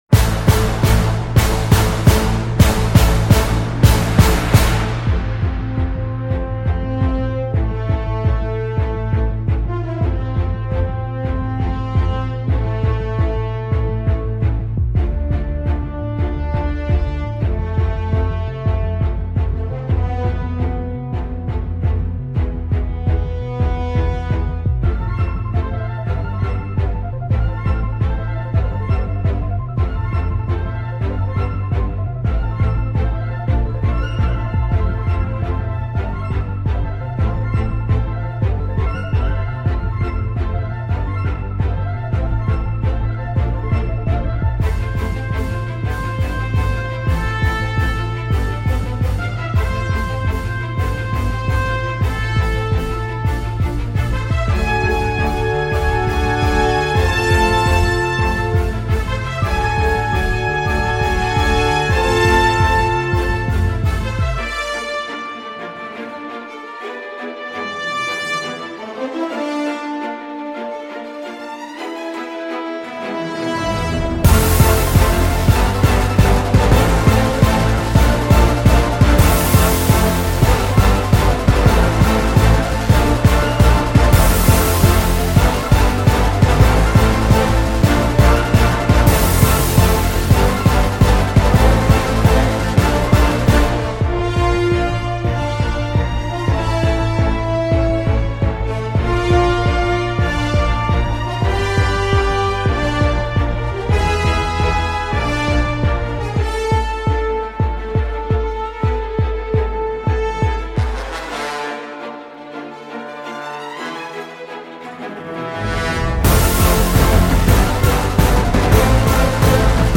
Loopé et raté.